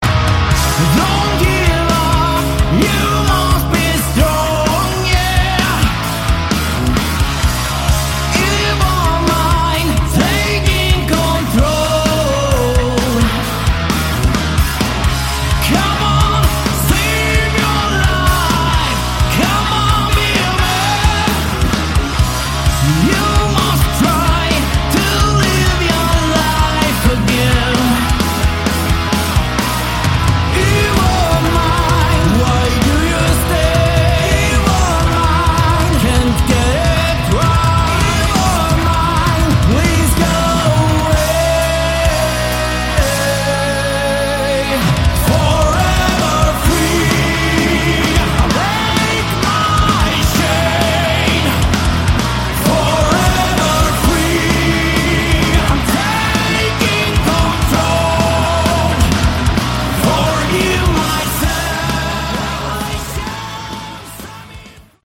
Category: Hard Rock
lead vocals
lead guitar
bass
drums